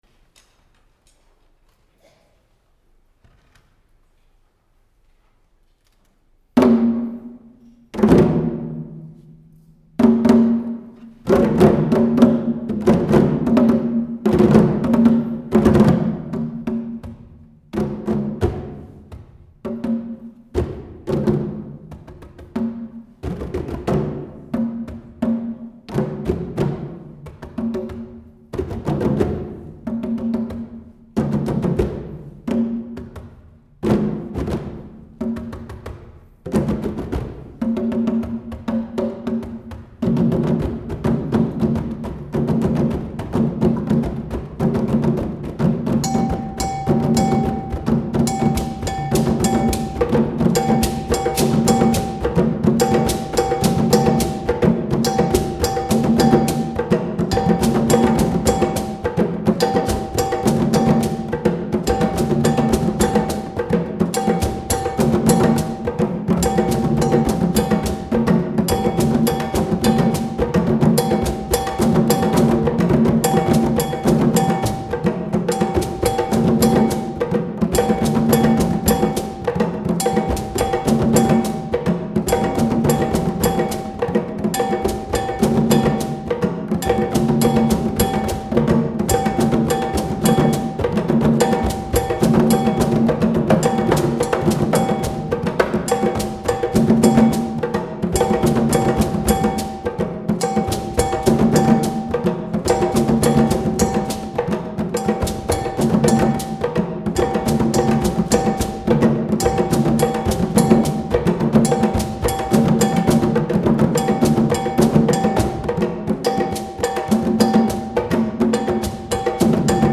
UVM Percussion Ensemble - Fall 2023
audio of performance